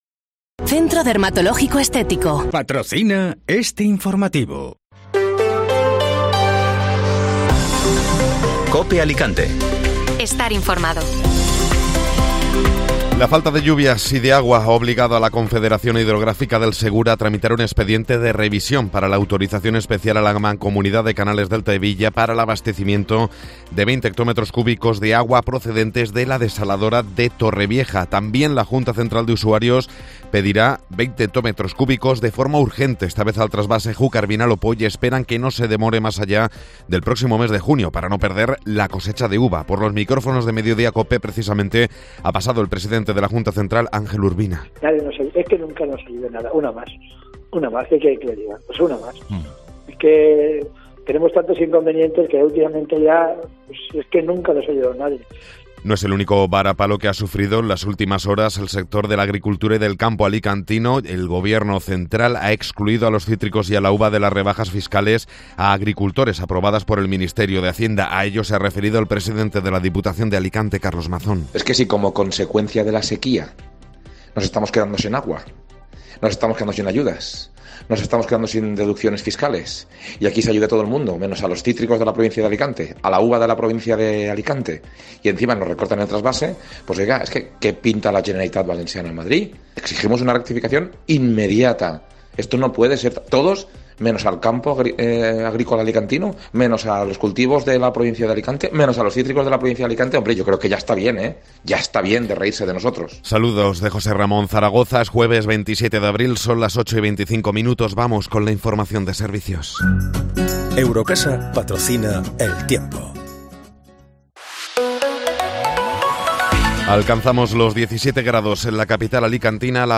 Informativo Matinal (Jueves 27 de Abril)